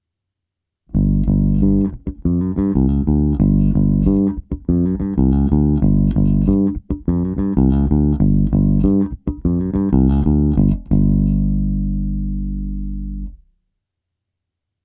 kombinace čistého zvuku a simulace.
Má to tlak, máto charakter, má to prostor, má to dostatek vyšších středů i výšek.